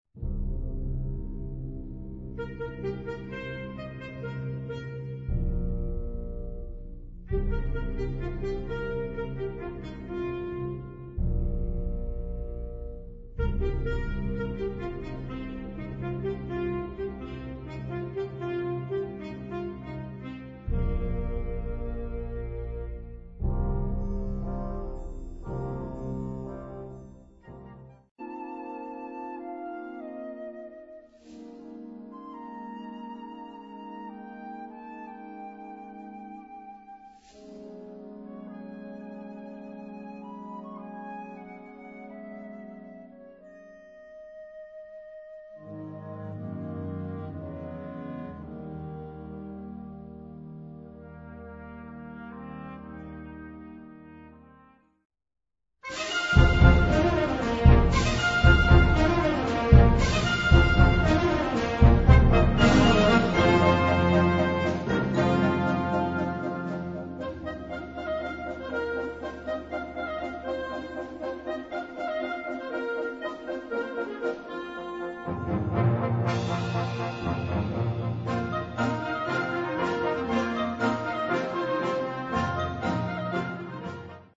Unterkategorie Konzertmusik